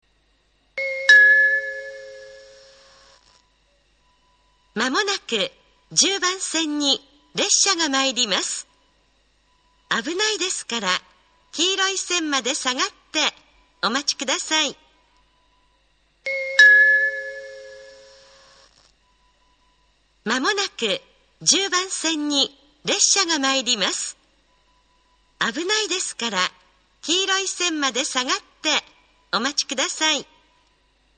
仙石線ホームは地下にあり、通常のROMベルを使用しています。
１０番線接近放送